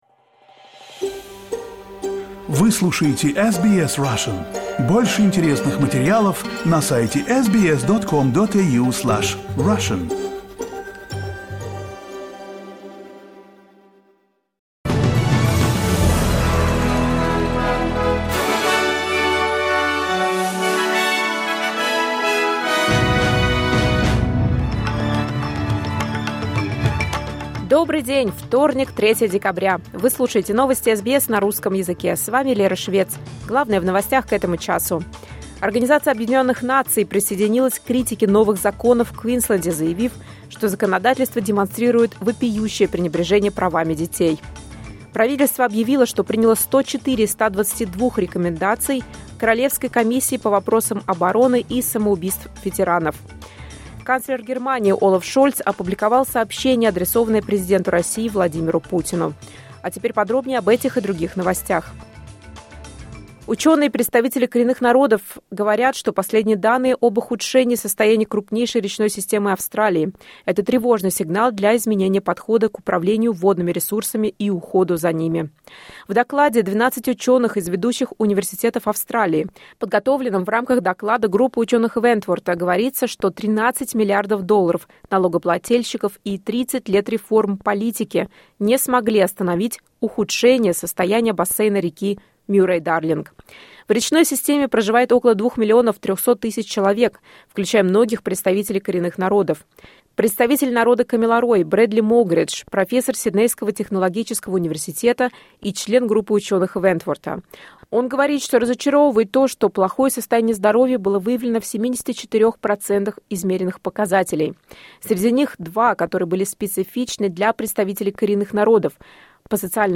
Новости SBS на русском языке — 03.12.2024